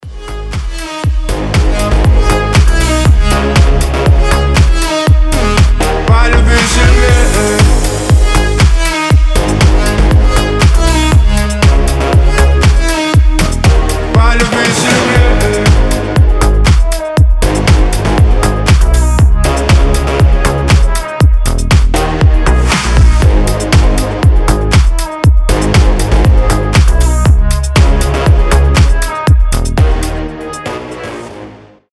• Качество: 320, Stereo
мужской голос
громкие
Club House
басы
ремиксы
Клубный звонок с мощными басами